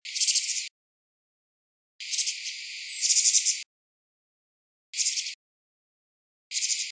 49-1紋翼畫眉.mp3
物種名稱 臺灣斑翅鶥 Actinodura morrisoniana
錄音地點 南投縣 信義鄉 乙女瀑布
xx 錄音環境 xx 發聲個體 行為描述 鳥叫 錄音器材 錄音: 廠牌 Denon Portable IC Recorder 型號 DN-F20R 收音: 廠牌 Sennheiser 型號 ME 67 標籤/關鍵字 備註說明 MP3檔案 49-1紋翼畫眉.mp3